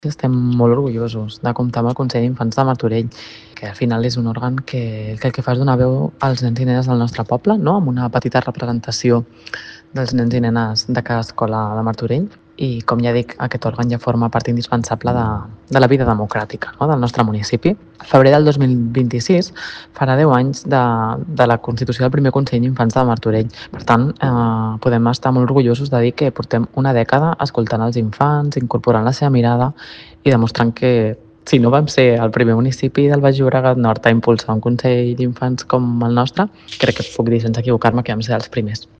Belén Leiva, regidora d'Infància i Adolescència